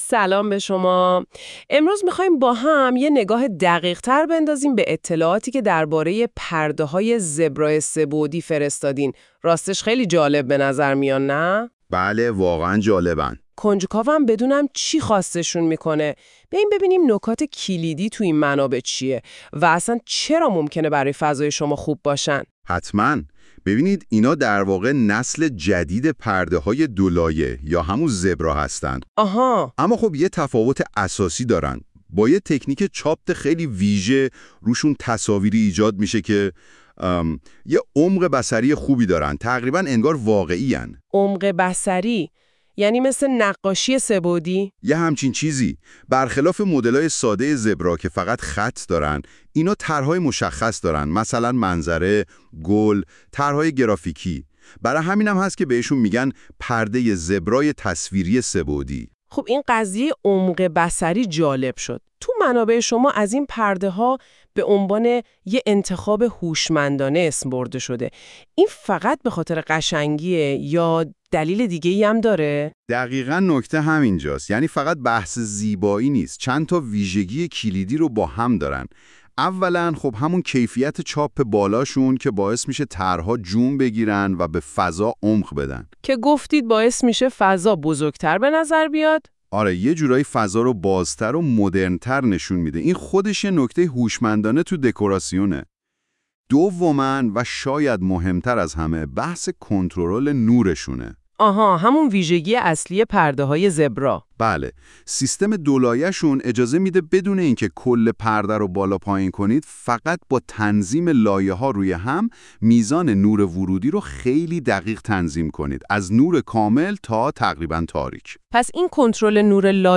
🎧 پادکست این محصول به کمک هوش مصنوعی تولید شده است.
برای راحتی شما، راهنمای کامل خرید این محصول را می‌توانید در این فایل صوتی بشنوید. ممکن است تلفظ برخی کلمات ایراداتی داشته باشد، اما محتوای اصلی به‌خوبی منتقل می‌شود.